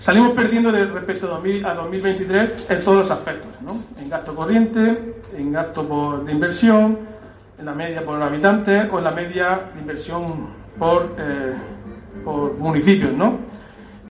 Diego José Mateos, portavoz del PSOE